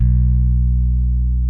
B1 1 F.BASS.wav